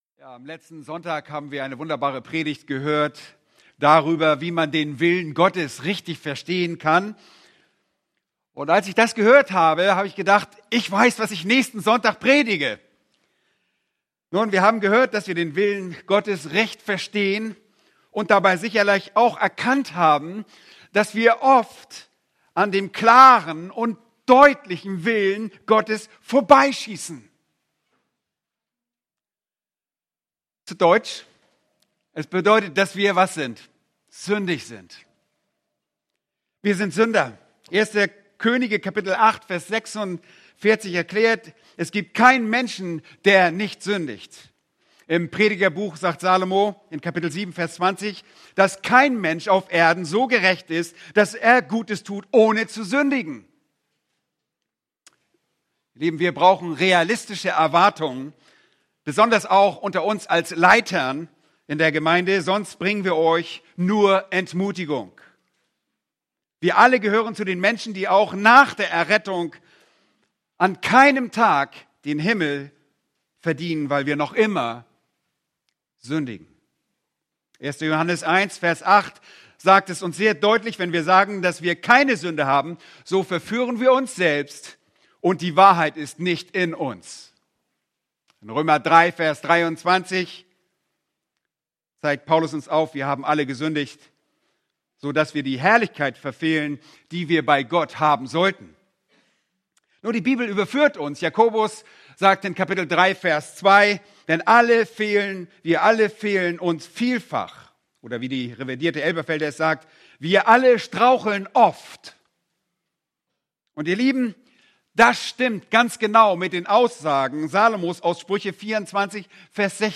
Eine predigt aus der serie "Weitere Predigten." Psalm 32,1-11